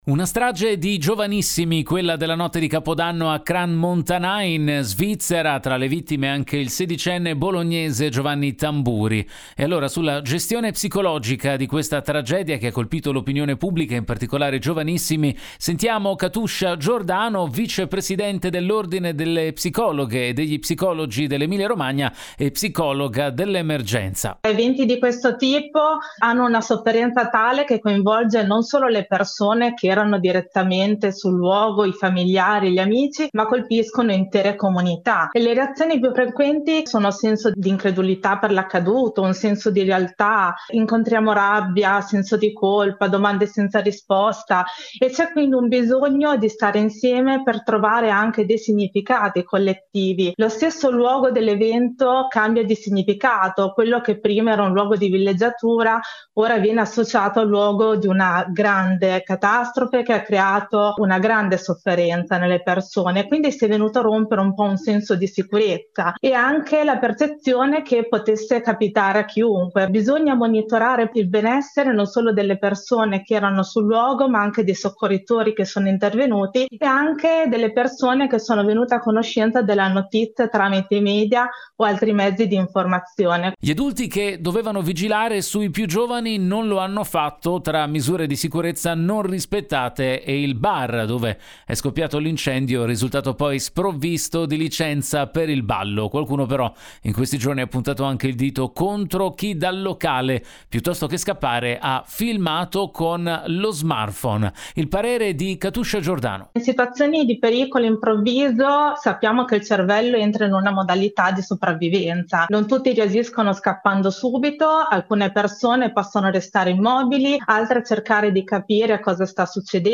7 gennaio 2026 - GR Radio Bruno, ed. delle 7:45, 9 e 10